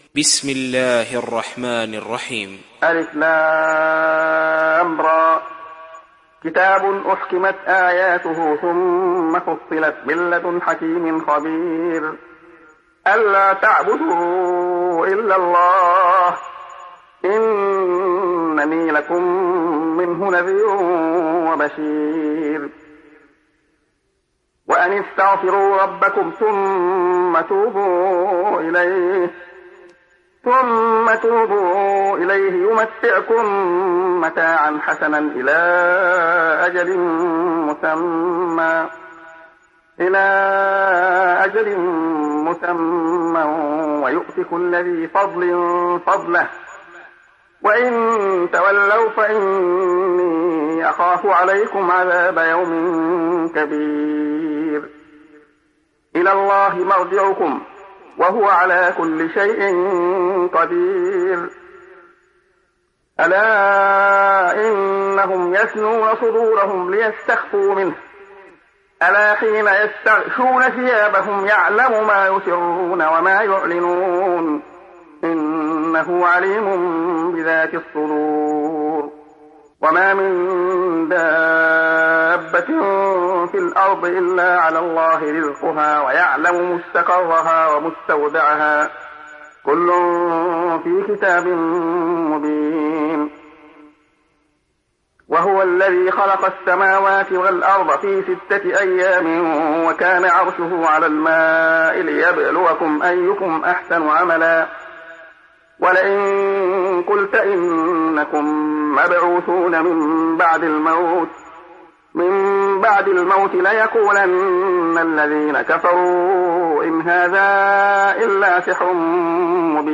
Hud Suresi İndir mp3 Abdullah Khayyat Riwayat Hafs an Asim, Kurani indirin ve mp3 tam doğrudan bağlantılar dinle